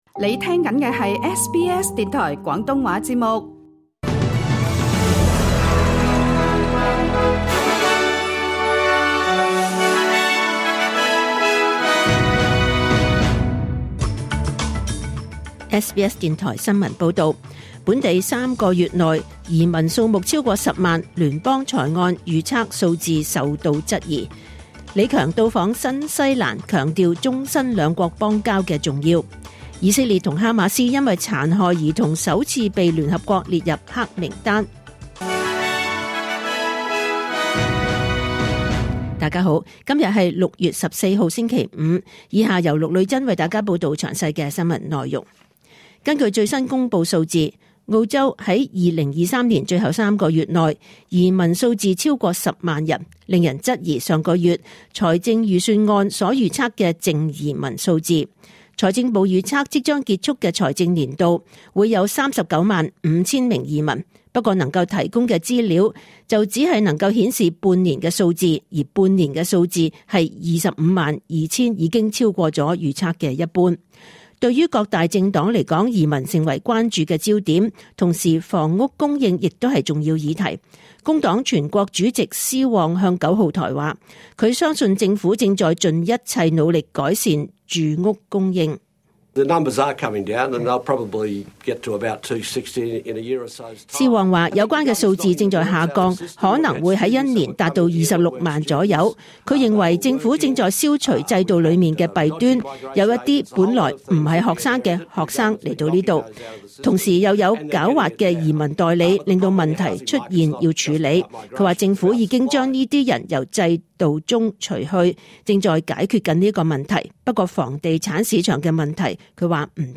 2024 年 6 月 14 日 SBS 廣東話節目詳盡早晨新聞報道。